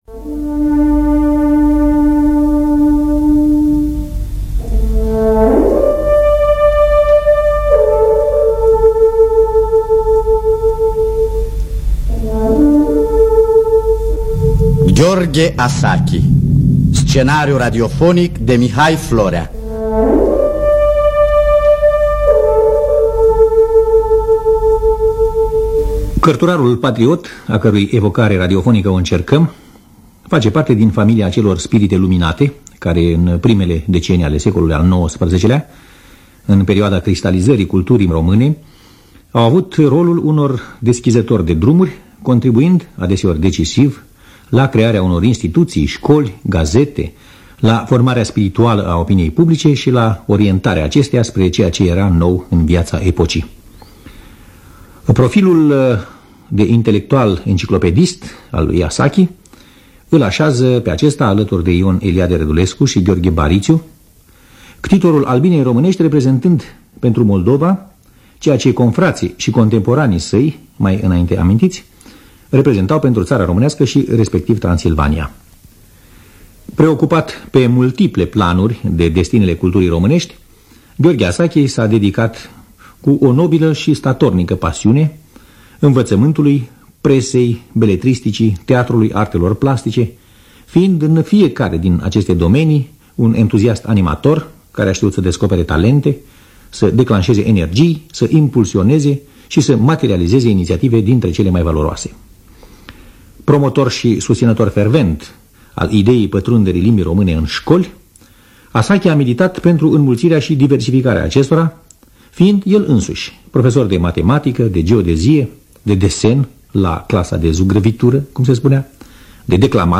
Scenariu radiofonic de Mihai Florea.